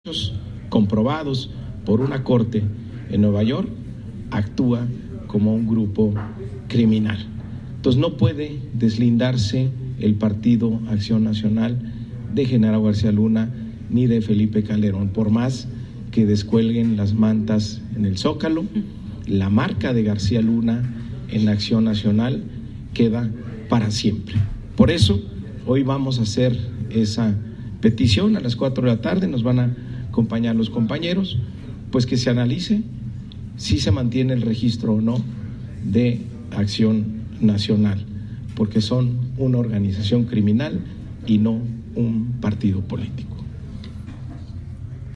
En conferencia de prensa, dijo que estarán en ese órgano electoral a las 16 horas, para hacer esa petición.